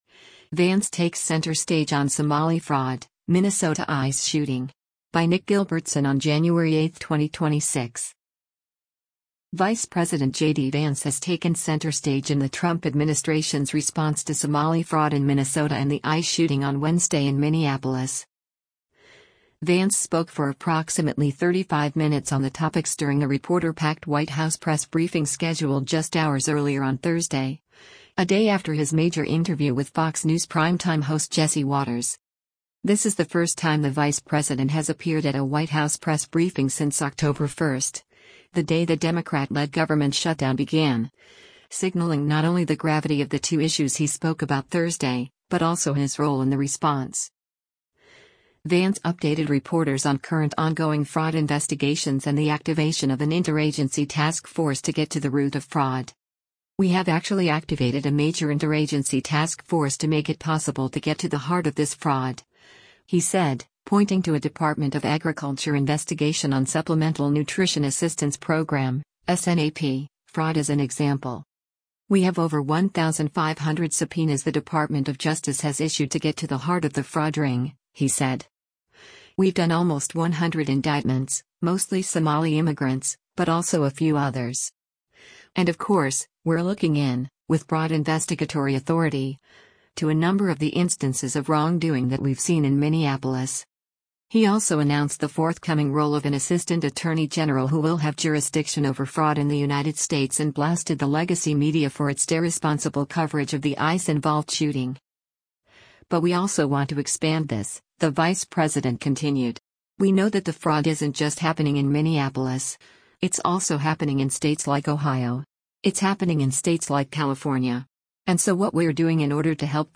Vice President JD Vance speaks during a briefing at the White House, Thursday, Jan. 8, 202